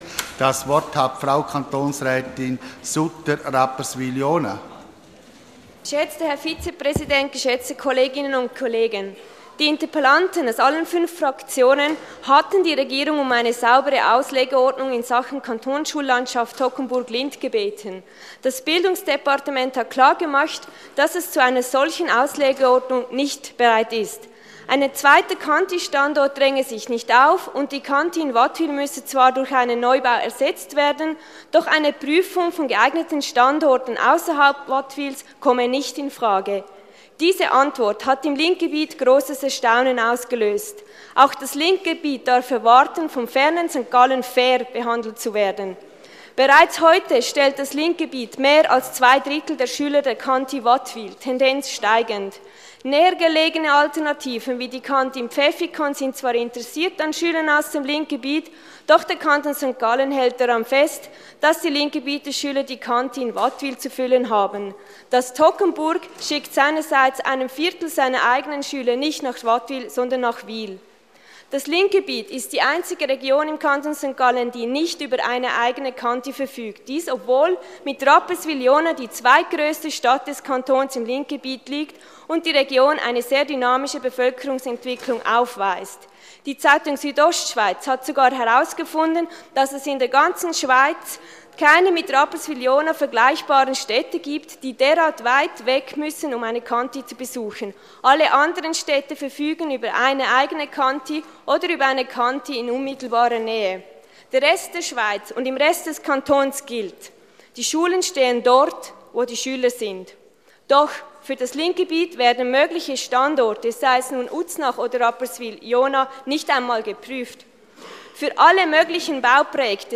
16.9.2013Wortmeldung
Session des Kantonsrates vom 16. bis 18. September 2013